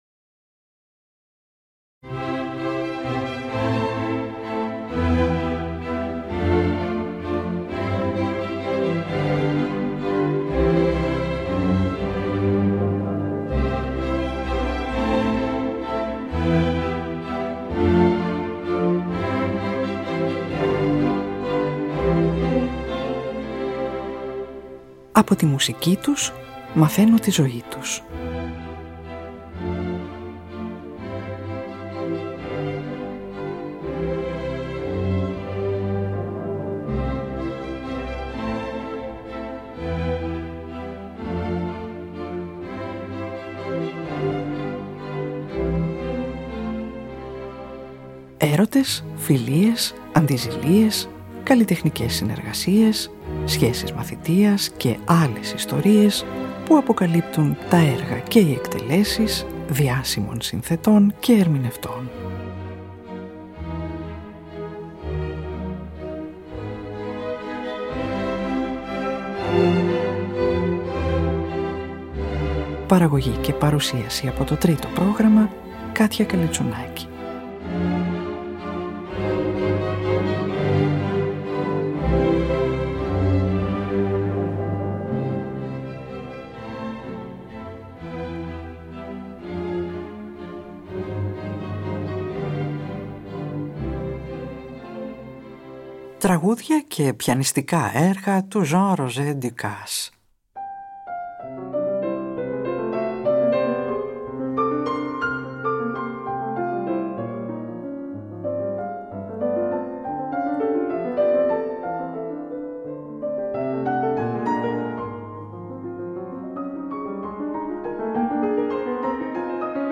Τραγούδια και Πιανιστικά Έργα του Jean Roger-Ducasse | 16.03.2025
mezzosoprano
πιανίστα